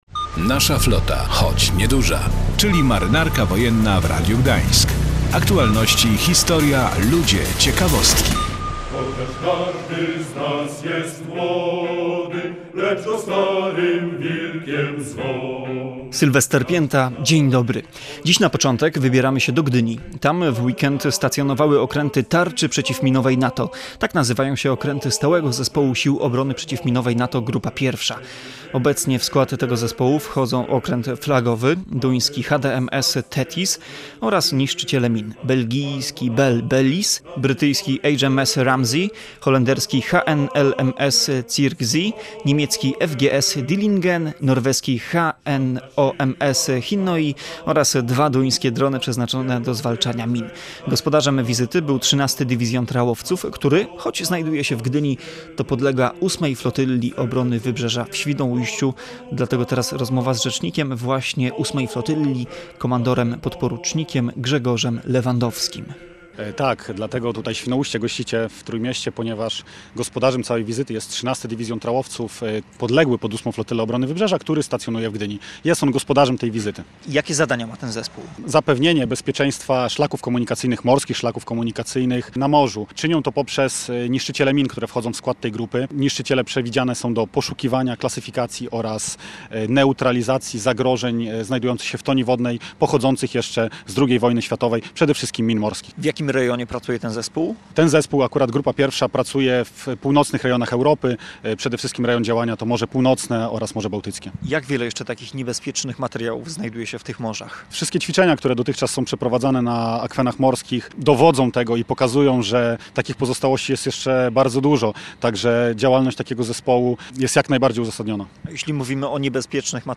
Potem rozmowa, która może część z państwa zaskoczyć.